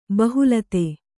♪ bahulate